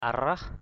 /a-rah/ (d.) con rệp = punaise. bug, bed-bugs.